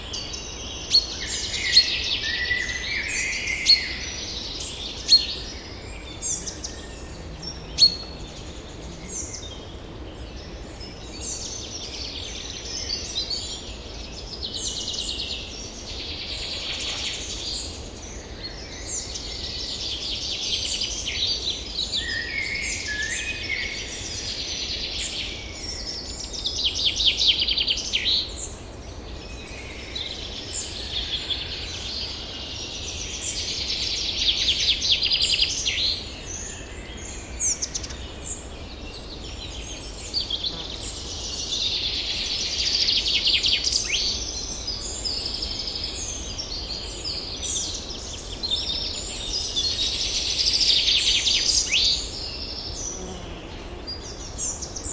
forest-day-1.ogg